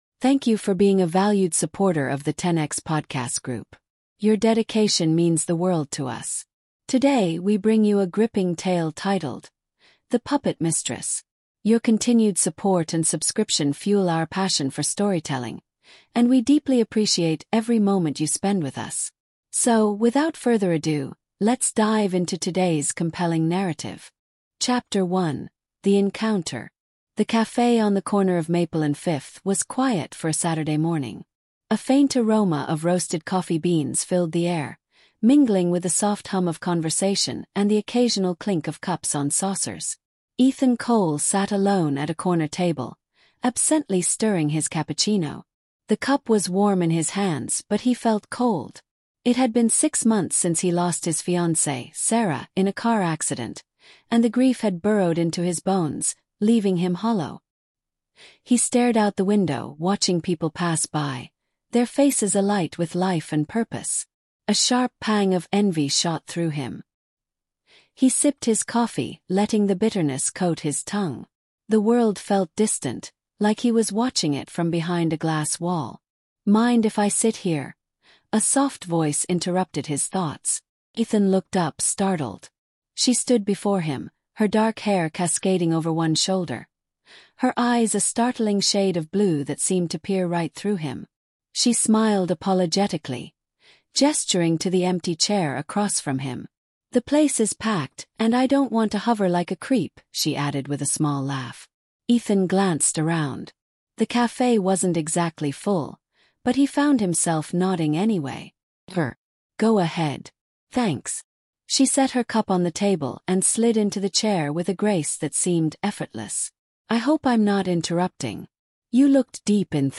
The Puppet Mistress is a thrilling storytelling podcast that delves into the art of manipulation, trust, and betrayal. Follow Ethan, a grieving man caught in the web of a charming con artist, Sophia, who manipulates him into committing crimes under the guise of love and justice. As the truth unravels, Ethan must confront the dark reality of Sophia’s intentions and decide whether to escape her grasp or continue down a dangerous path.